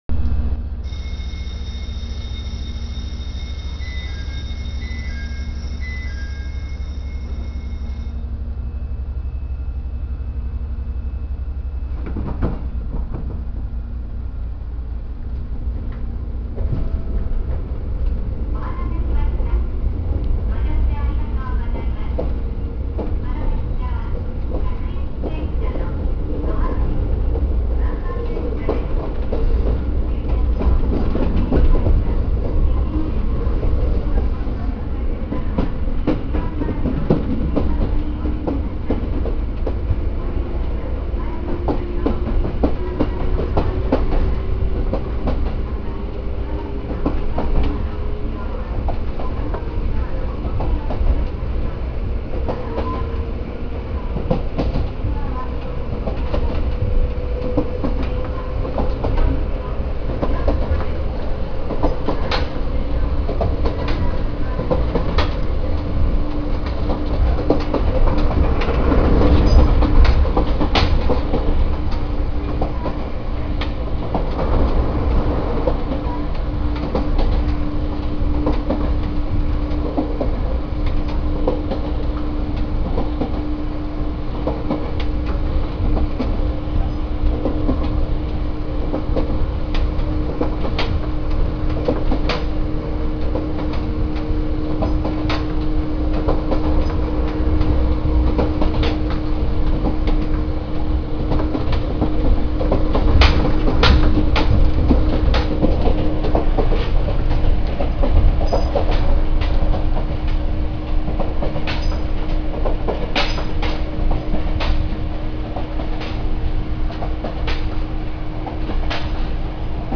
・7700系走行音
【石川線】鶴来→日御子（2分26秒：797KB）
抵抗制御故に大した音の違いも無いのですが、降圧に伴う電装品の換装を受けている事から8000系列とは走行音は異なる…筈です。また、ドアチャイムが設置されているのも8000系列と異なる所。